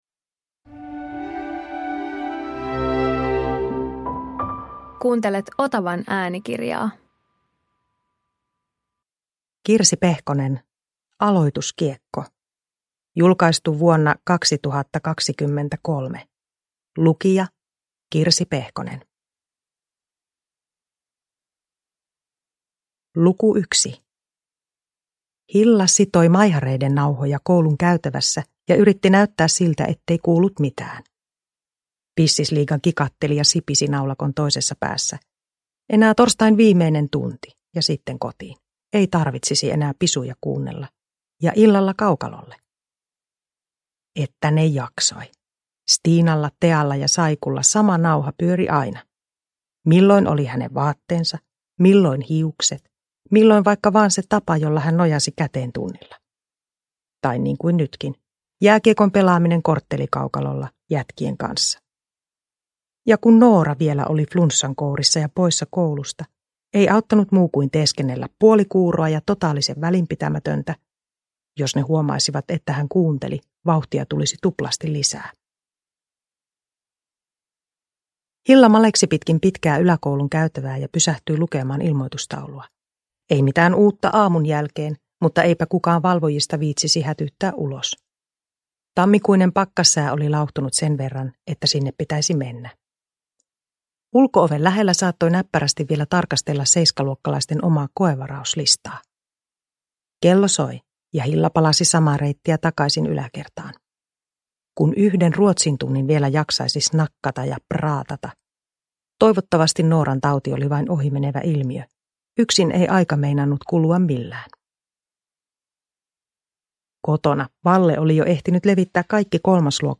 Aloituskiekko – Ljudbok – Laddas ner